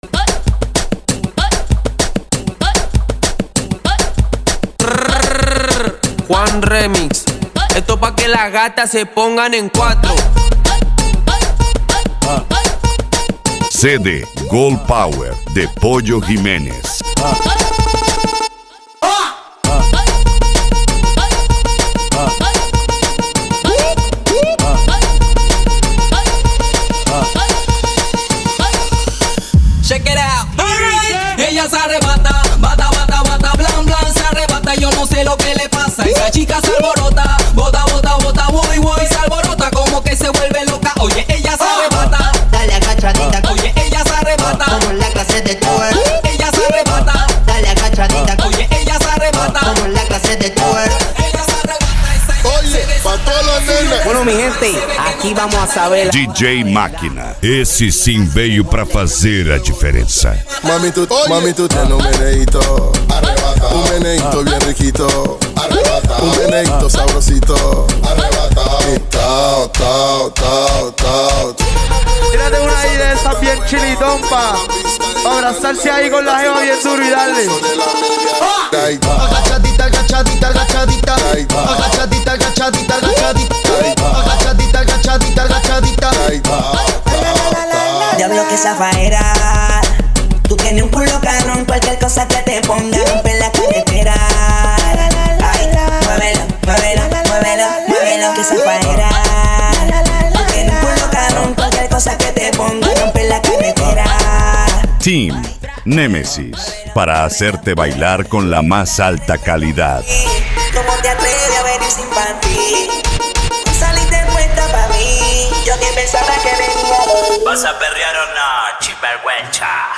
Cumbia
Reggae
Reggaeton